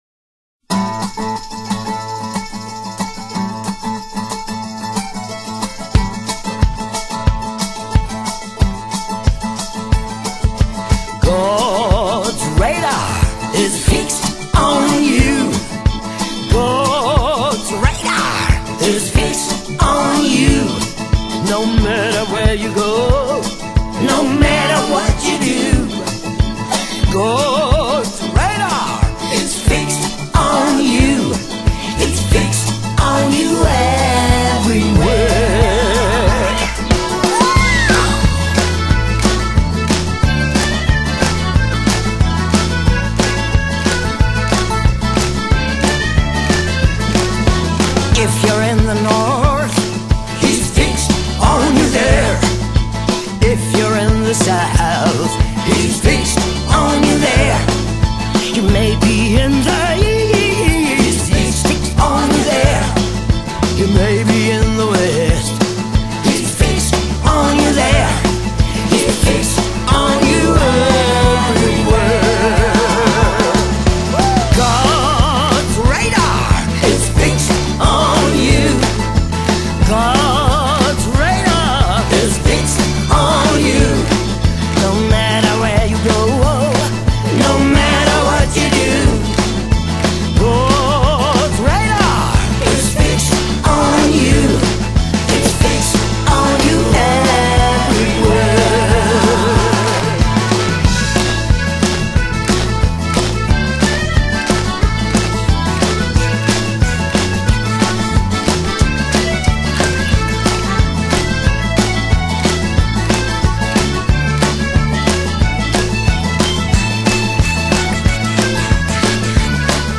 音樂風格︰Pop, Alternative | 1CD |
德國歌特Punk教母。